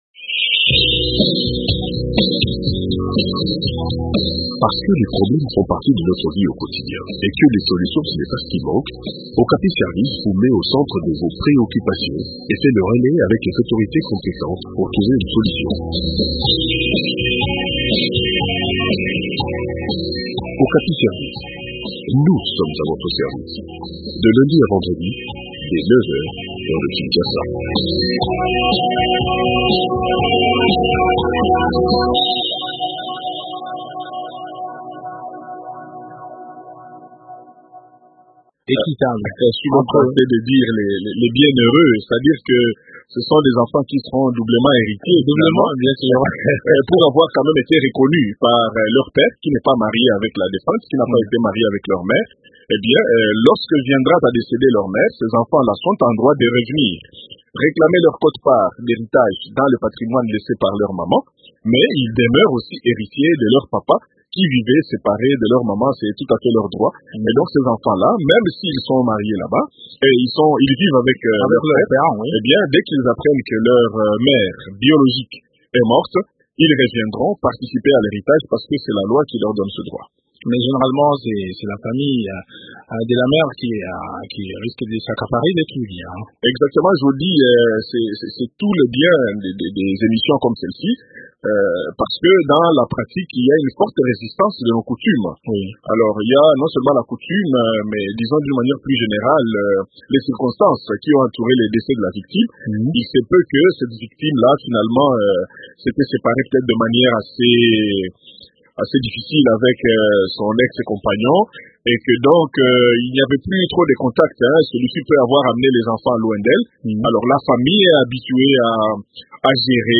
juriste indépendant